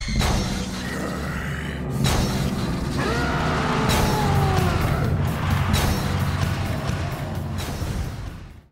Rage Scream